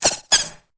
Cri_0810_EB.ogg